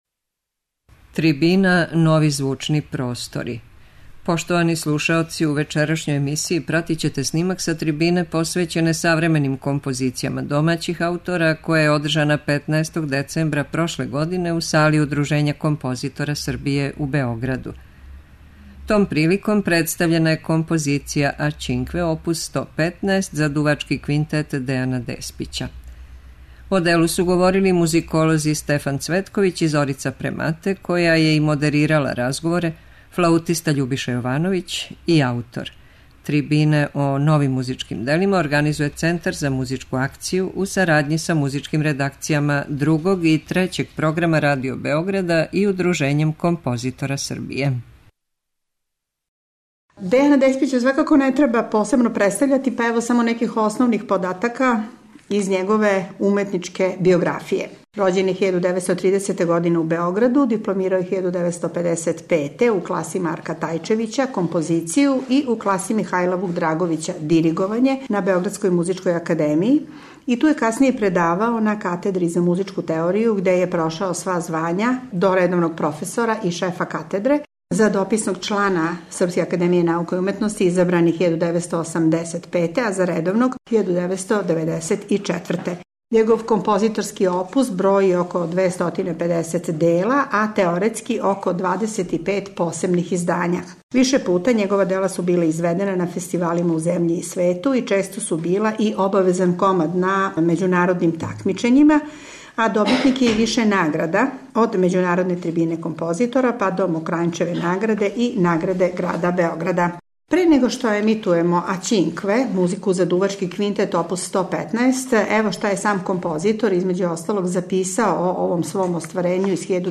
Пратићете снимак са трибине посвећене савременим композицијама домаћих аутора, која је одржана 15. децембра прошле године у сали Удружења композитора Србије у Београду. Том приликом представљена је композиција „A cinque”, опус 115 за дувачки квинтет Дејана Деспића.